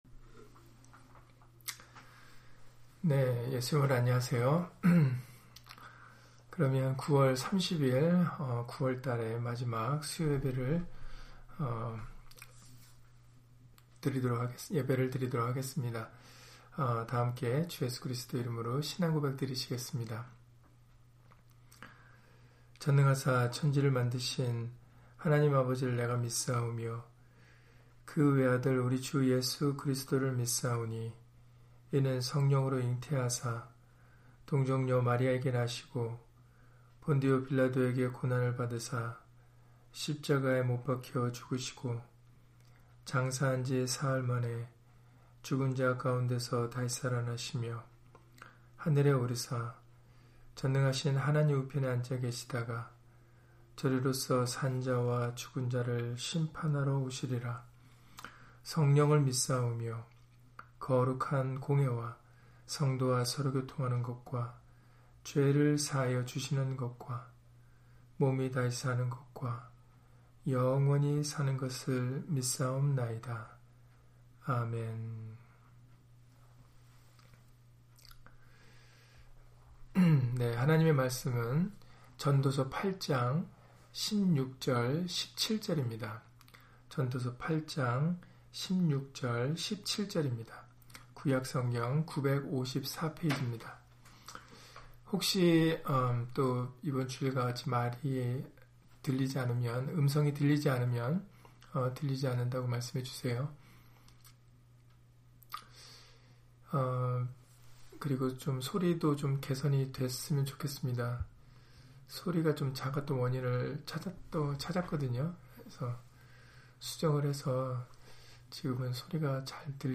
전도서 8장 16-17절 [사람이 능히 깨달을 수 없도다] - 주일/수요예배 설교 - 주 예수 그리스도 이름 예배당